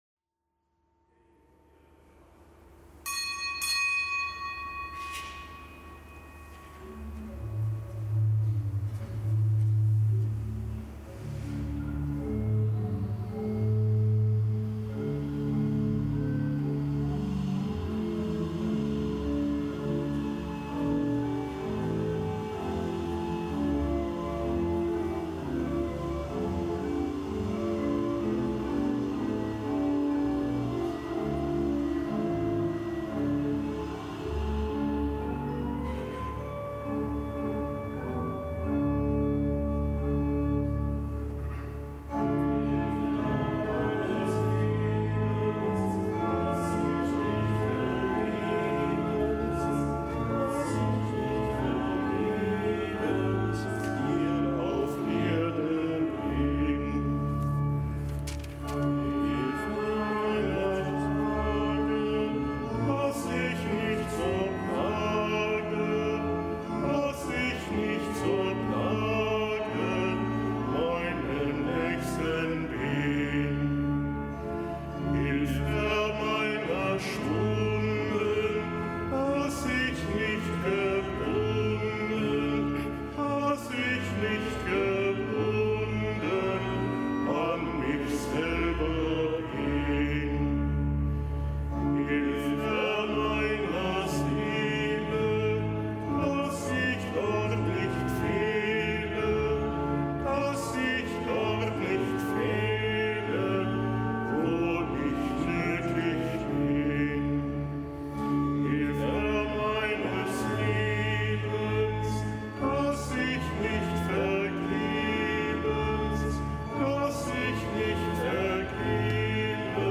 Kapitelsmesse aus dem Kölner Dom am Donnerstag der ersten Fastenwoche